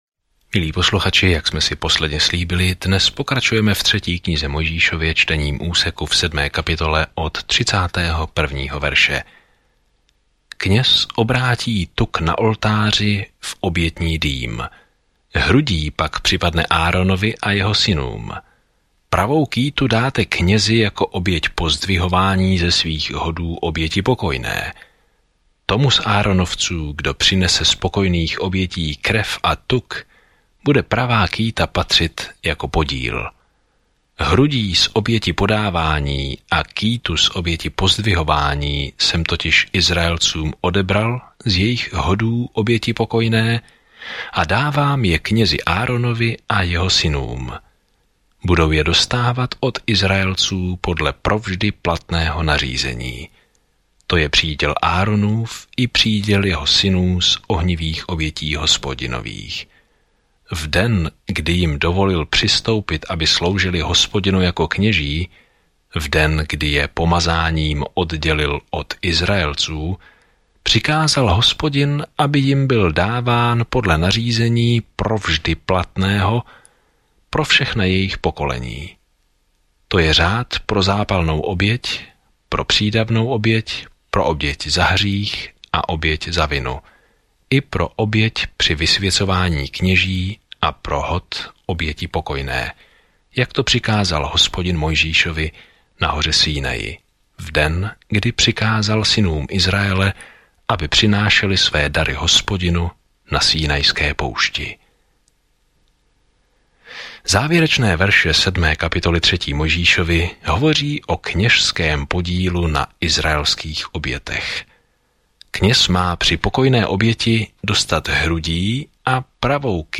V uctívání, obětování a úctě Leviticus odpovídá na tuto otázku starověkému Izraeli. Denně procházejte Leviticus a poslouchejte audiostudii a čtěte vybrané verše z Božího slova.